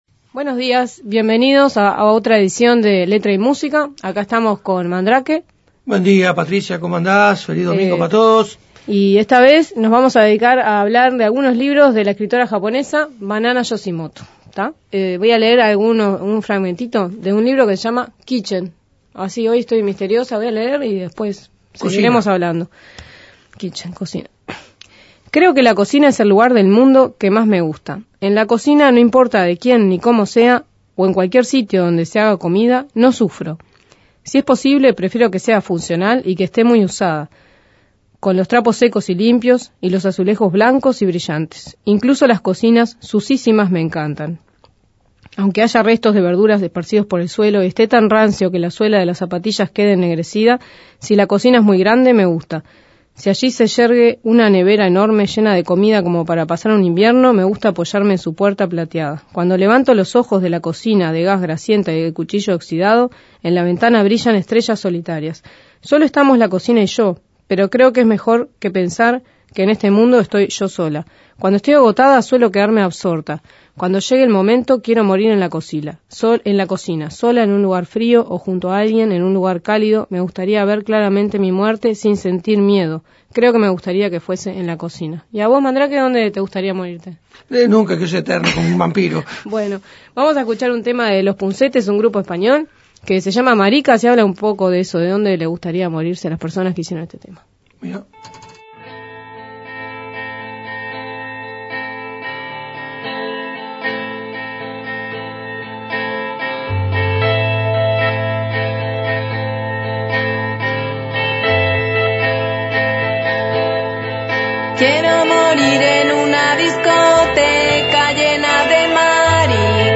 Descargar Audio no soportado Leímos pasajes de "Kitchen", su primera novela.